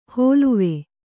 ♪ hōluve